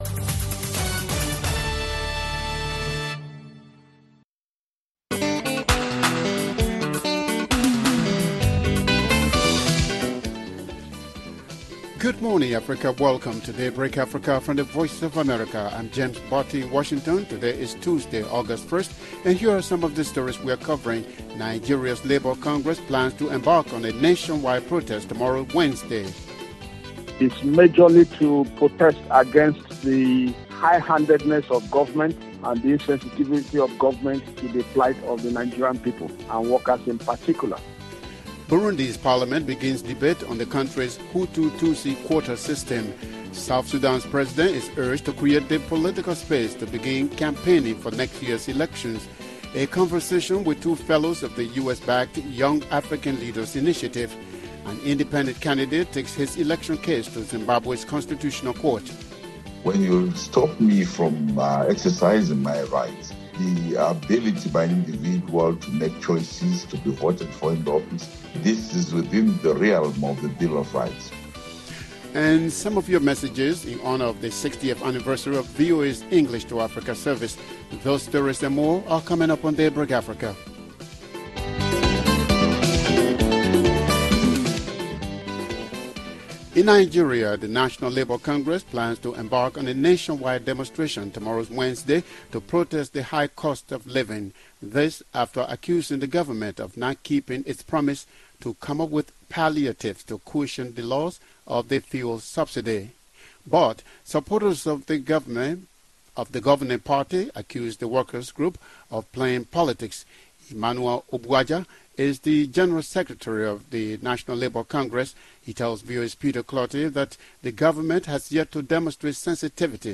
A conversation with two fellows of the US-backed Young African Leaders Initiative and an independent candidate takes his case to Zimbabwe’s constitutional court.